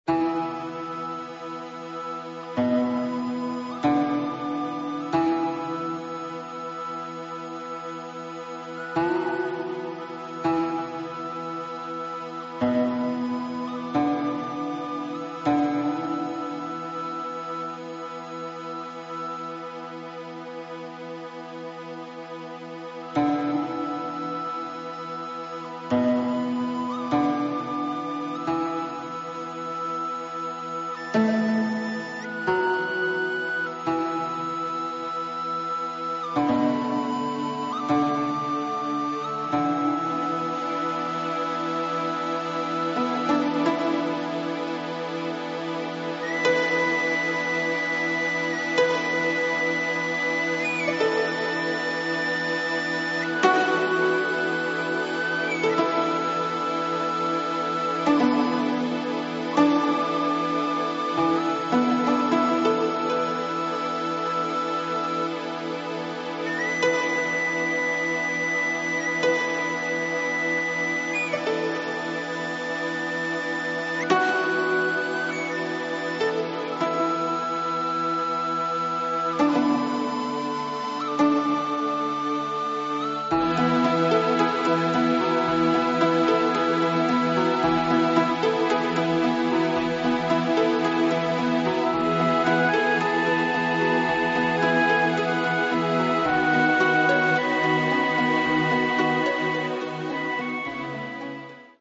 作曲・編曲・ネパールの縦笛とシンセサイザー演奏